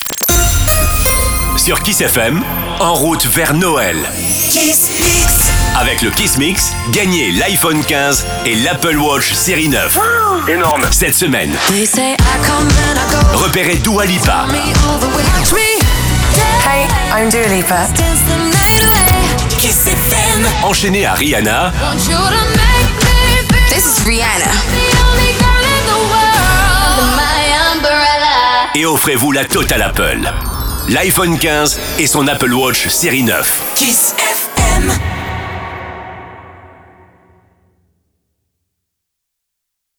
Station Voiceover
His deep and reassuring voice makes him the official voice of several radio stations.
He records his voice daily on a NEUMANN TLM 49 microphone, a large diaphragm microphone with a renowned warm sound.